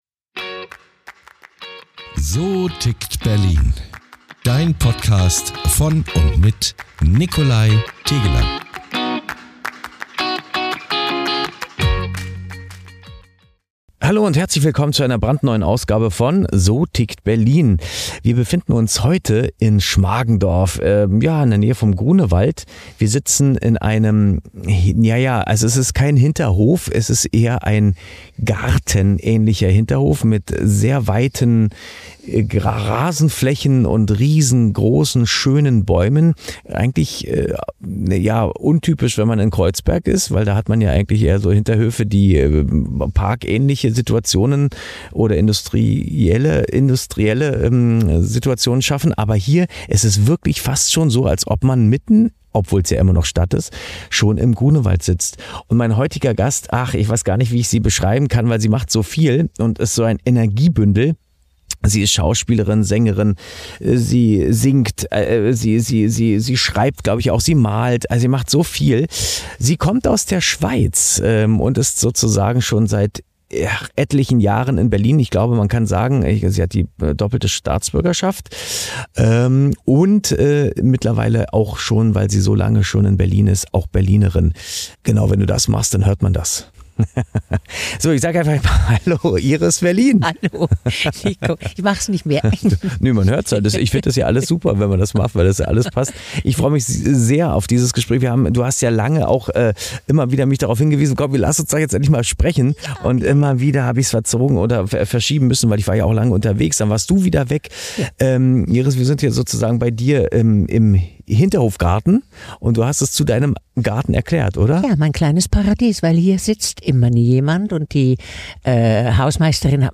Wie verändert sich das Theater, was bedeutet Relevanz auf der Bühne – und was macht eine Rolle wirklich zur Herausforderung? Ein Gespräch über Handwerk und Hingabe, über Zarah Leander und Shakespeare, über Schicksal und Selbstermächtigung.